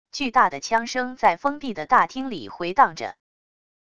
巨大的枪声在封闭的大厅里回荡着wav音频